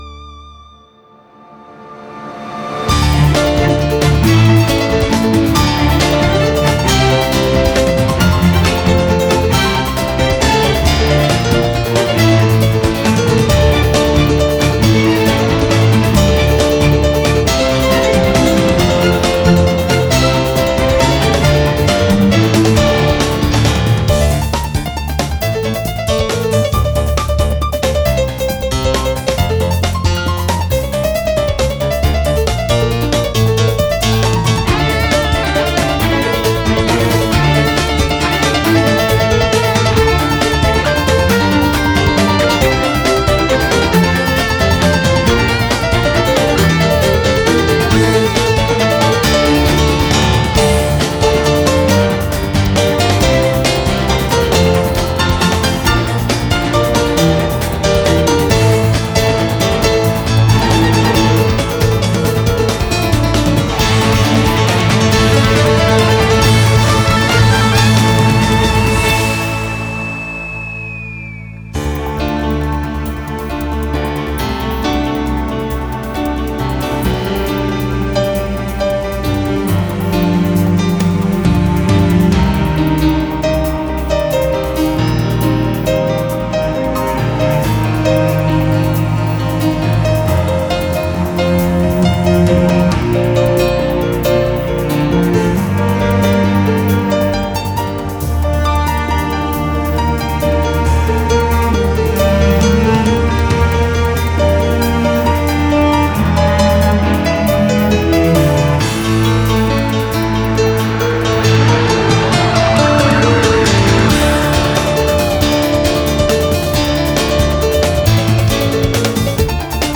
Фортепиано.